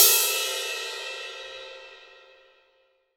Index of /90_sSampleCDs/AKAI S6000 CD-ROM - Volume 3/Crash_Cymbal1/18_22_INCH_CRASH
ROCK18CRS2-S.WAV